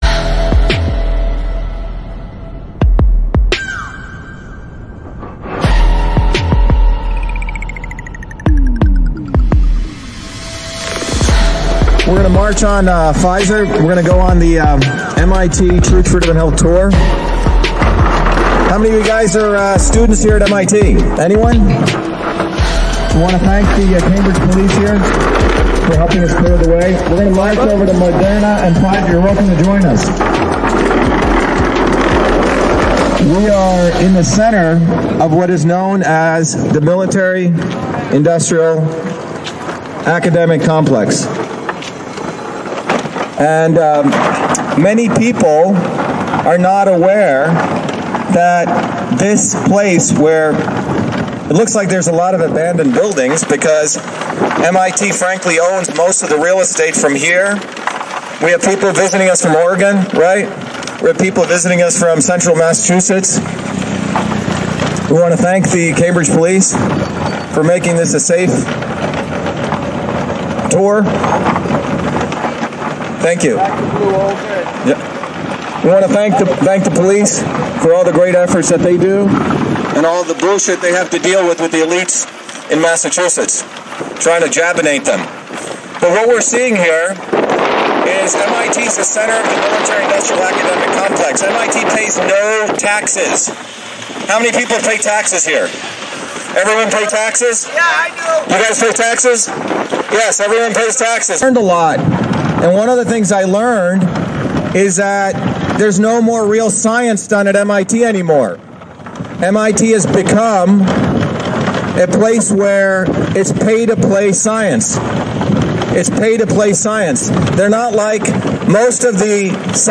Dr. Shiva in front of pfizer building